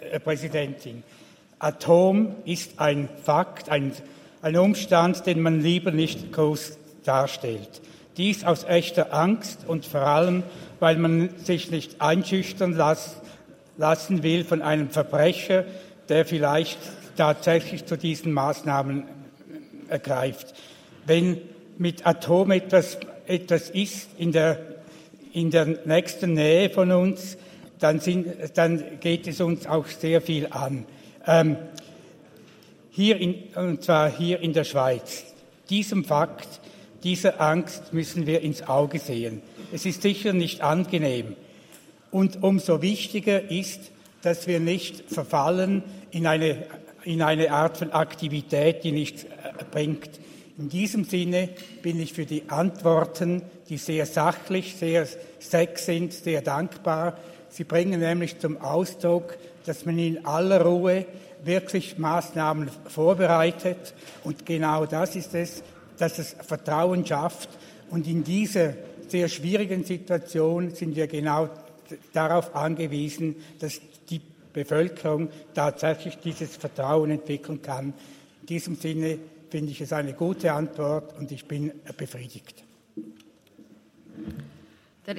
18.9.2023Wortmeldung
Session des Kantonsrates vom 18. bis 20. September 2023, Herbstsession